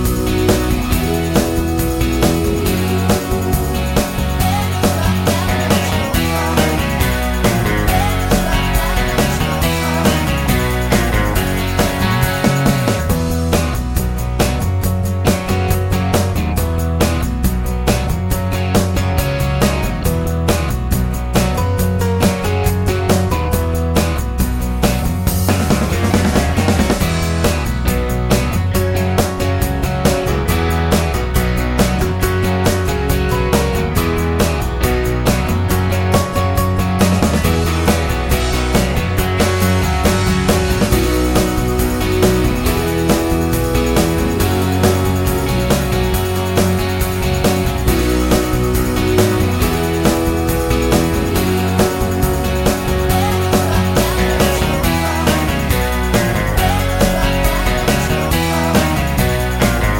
for trio Pop